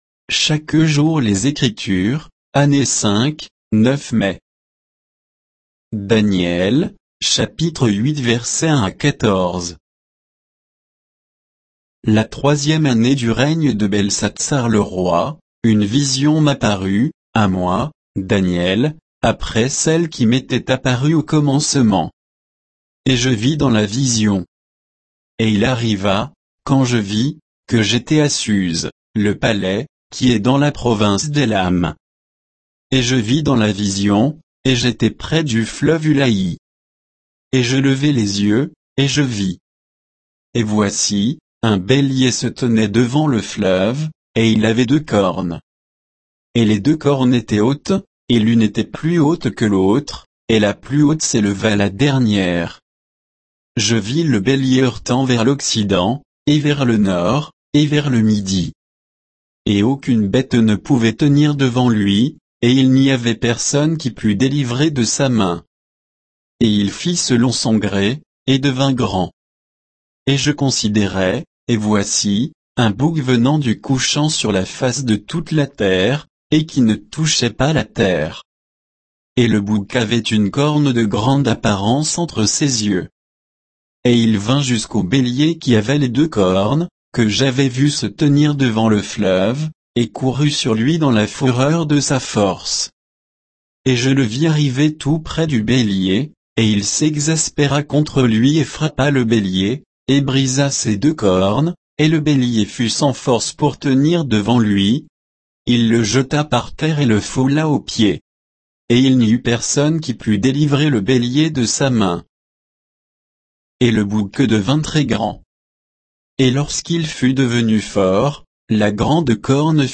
Méditation quoditienne de Chaque jour les Écritures sur Daniel 8, 1 à 14